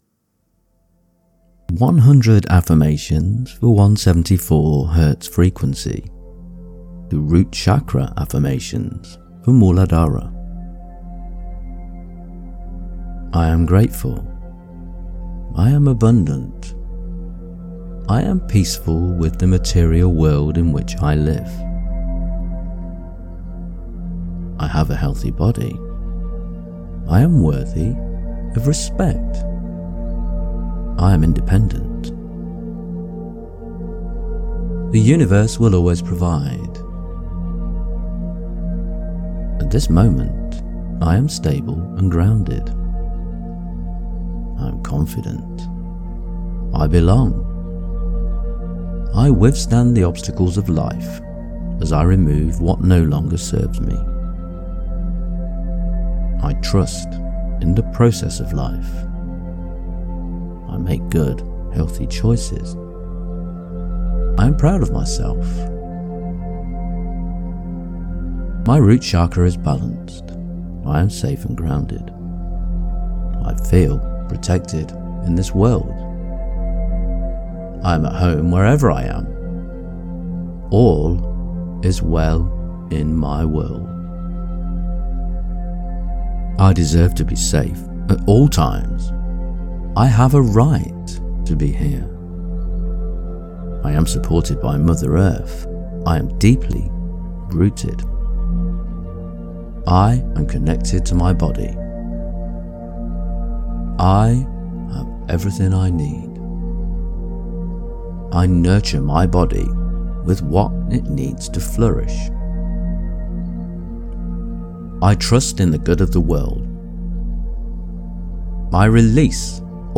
174 Frequency Pain Relief: The 174 Hz frequency is known for its ability to reduce pain and relieve physical discomfort.
Root Muladhara Chakra Affirmations